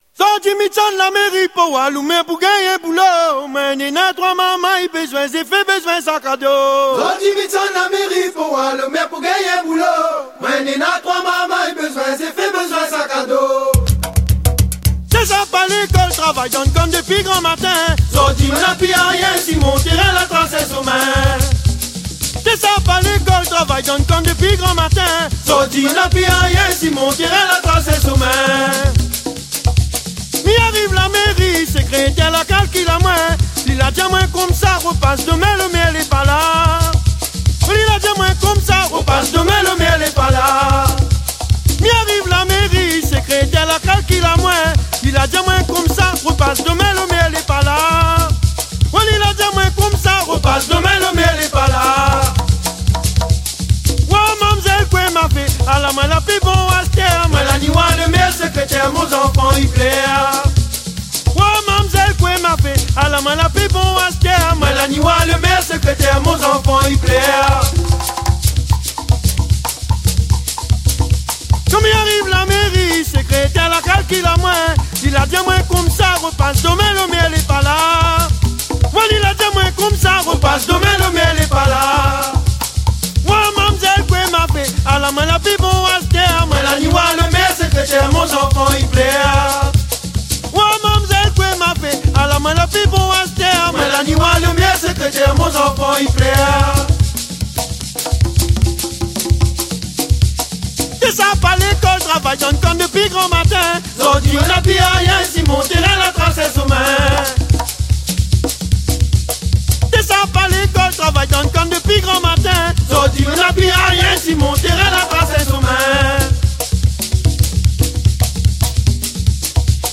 Chanson de campagne : Maloya La Méri Politique Mardi 25 Février 2014 Le son de la campagne Grand Sud… À Saint-Joseph, le maloya garde son pouvoir de débat…